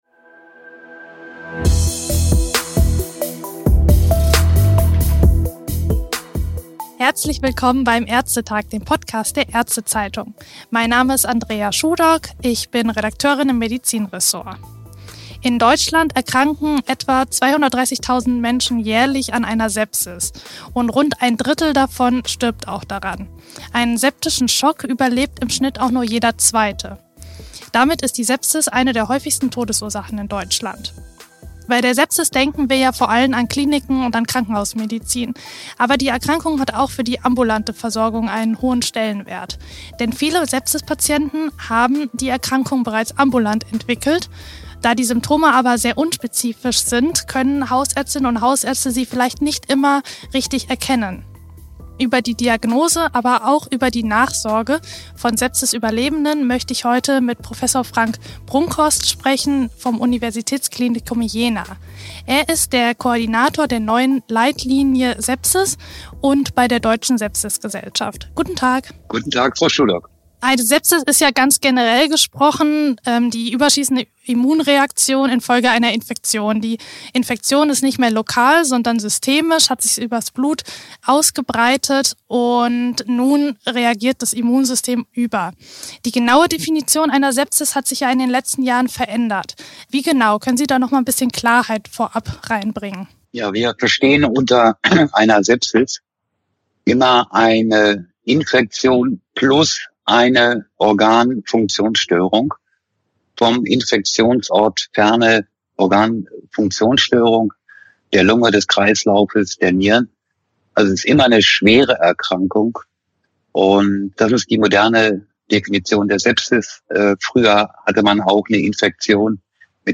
Zweiter Schwerpunkt des Podcast-Gesprächs ist die Nachsorge von Sepsis-Überlebenden.